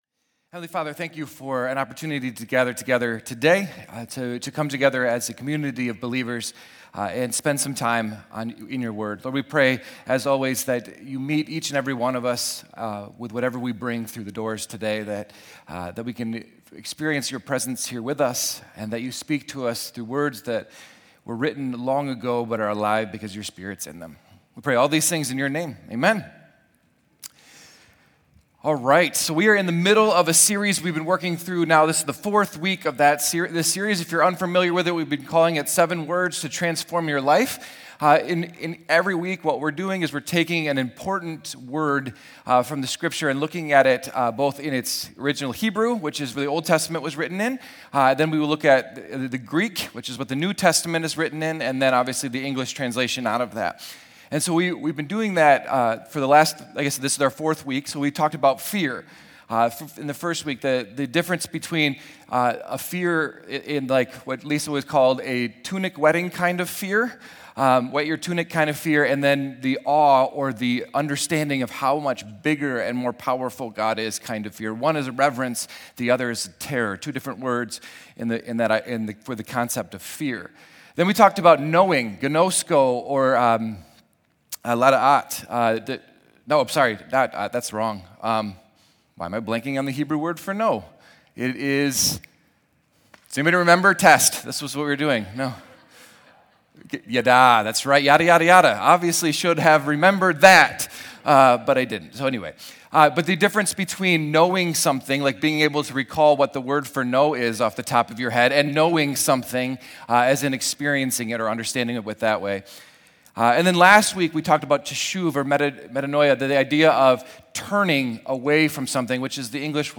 Harbor Life Sermons